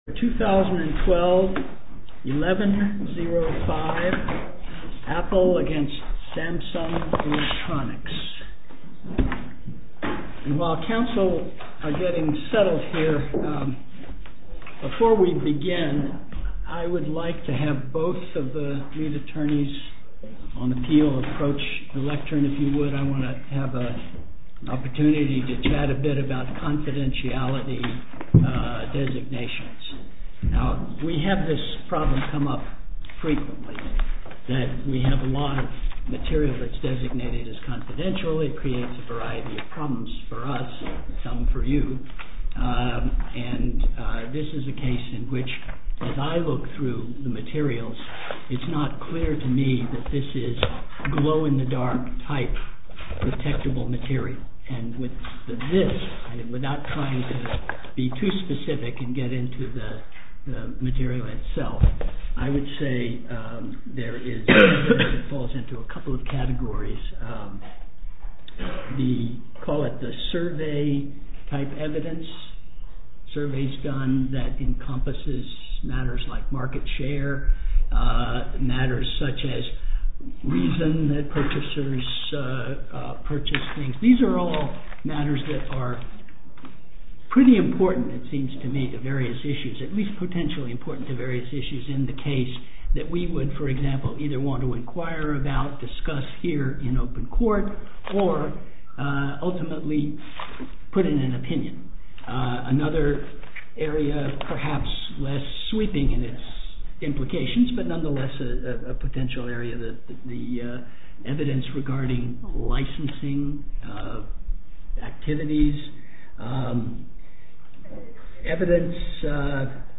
Oral argument audio posted: APPLE V. SAMSUNG ELECTRONICS (mp3) Appeal Number: 2012-1105 To listen to more oral argument recordings, follow this link: Listen To Oral Arguments.